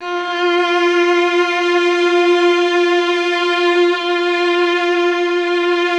MELLOTRON .6.wav